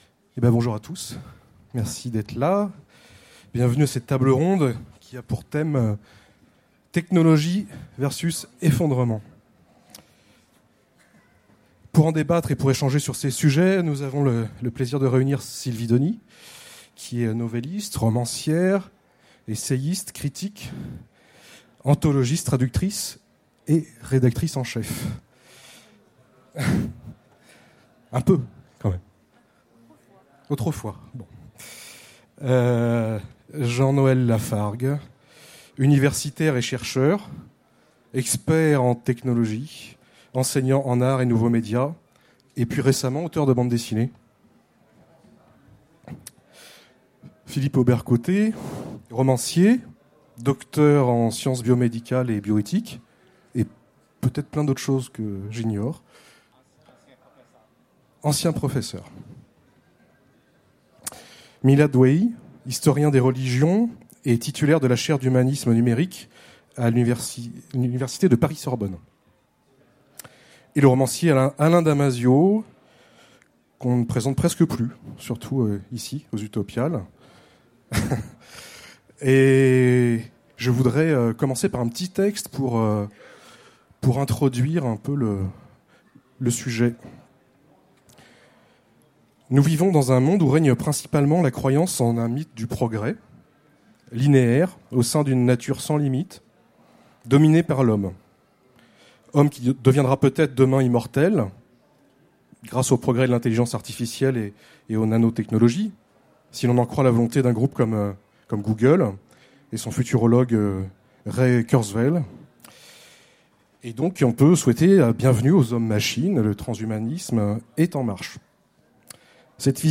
Utopiales 2016 : Conférence Technologie vs effondrement, 2 récits